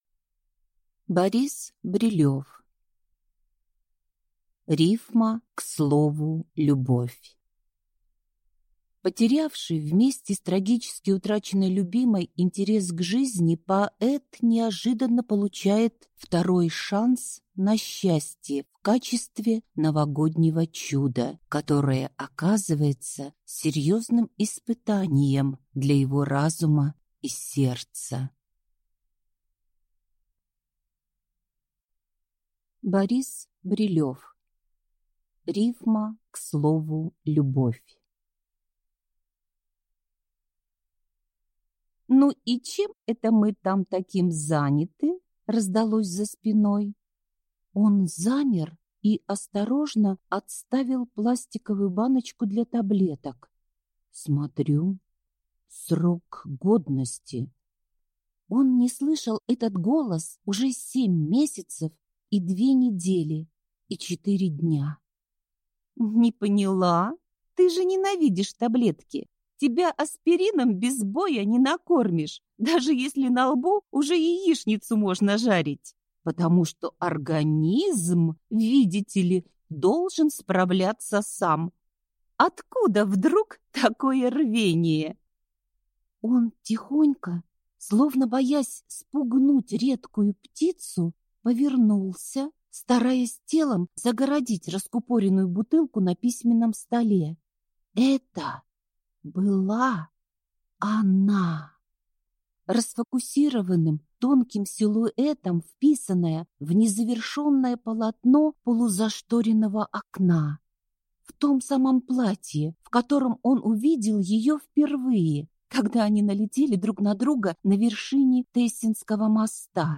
Аудиокнига РИФМА К СЛОВУ «ЛЮБОВЬ» | Библиотека аудиокниг